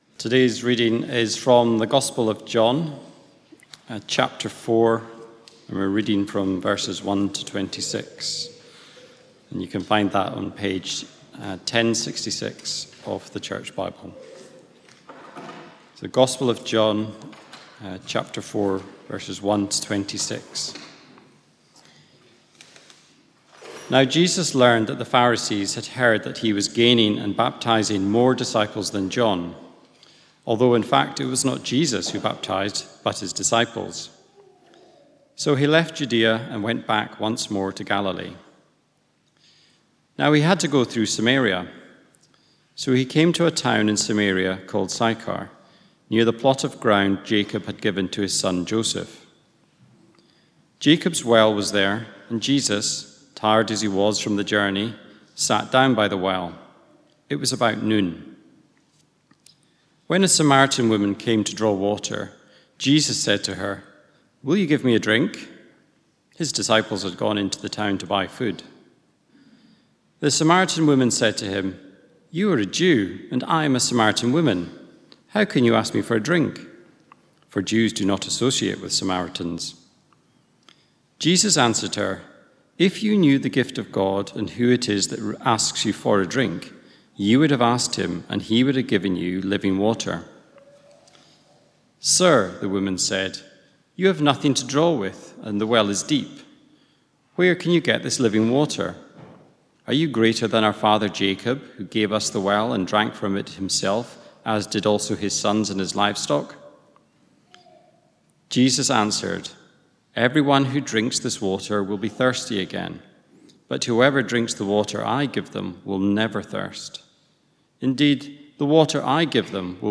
From Service: "10.15am Service"